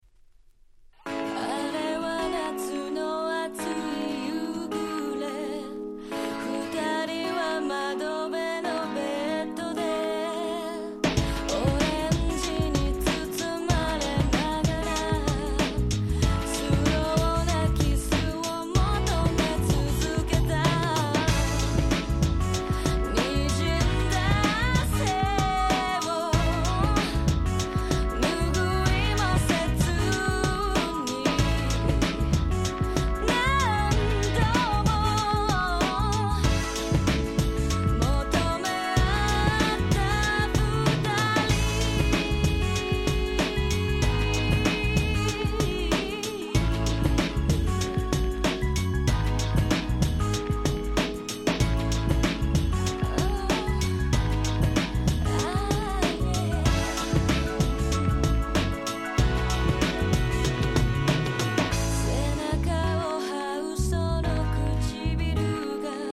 98' Nice Japanese R&B !!